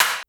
snr_27.wav